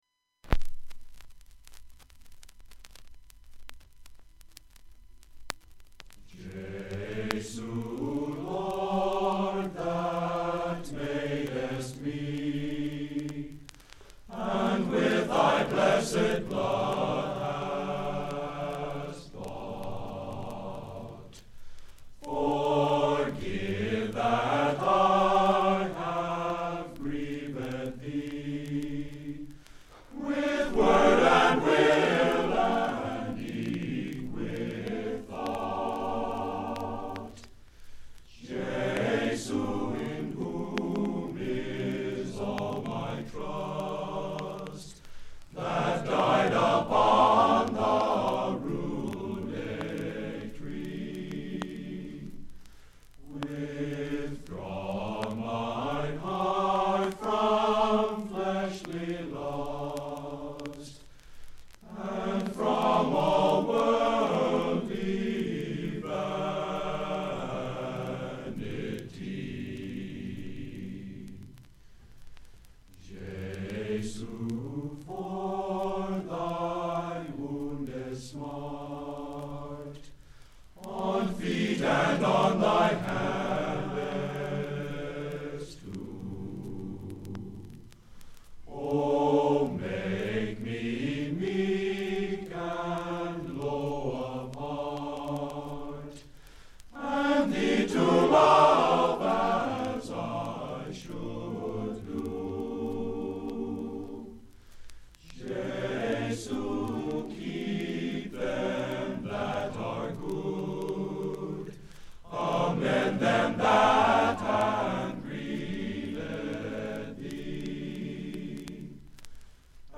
Includes a recording of the concert, and the album art from front and back covers.